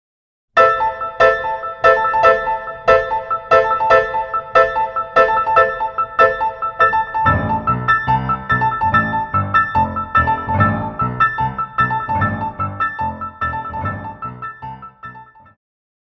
古典,流行
鋼琴
演奏曲
世界音樂
僅伴奏
沒有主奏
沒有節拍器